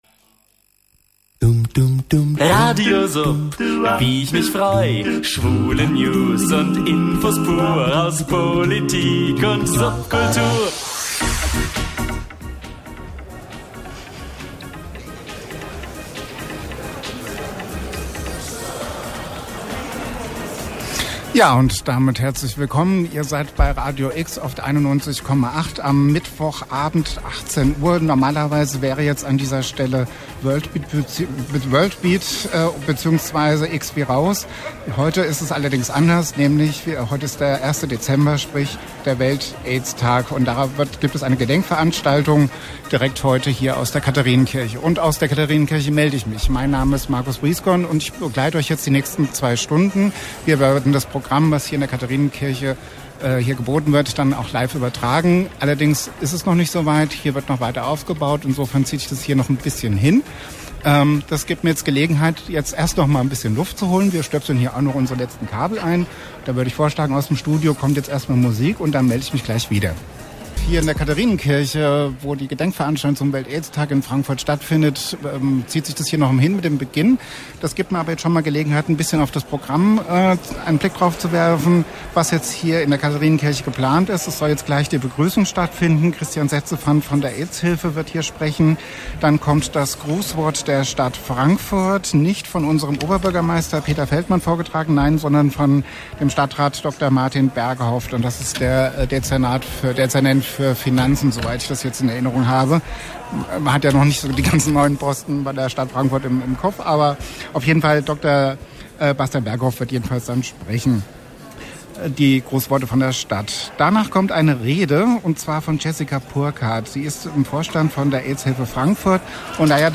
Sendung 1270-a: Live von der Welt-Aids-Tag-Veranstaltung ~ radioSUB Podcast
Unsere Liveübertragung aus der Katharinenkirche